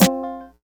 CG_Snr (44).WAV